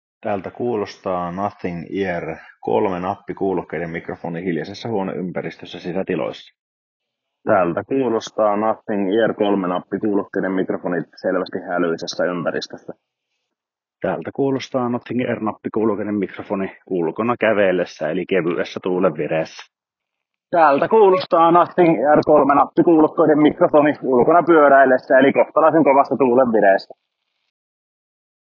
Mikrofonin ääniesimerkki
Mikrofonit Nothing Ear (3):ssa tuottavat läpi testitilanteiden kohtalaisen hyvää ääntä. Sisätiloissa hiljaisessa ympäristössä ääni on pääasiassa luonnollinen, kuten myös ulkona kävellessä. Hälyisässä ympäristössä ääni muuttuu selvästi kompressoituneeksi, mutta se säilyy kuitenkin selkeänä pitäen ympäristön äänet poissa. Ulkona pyöräillessä, eli kovassa tuulessa ääni on edelleen selkeä, vaikka äänen laatu itsessään muuttuukin jo todella käsitellyksi.
Nothing-Ear-3-mikrofonit.wav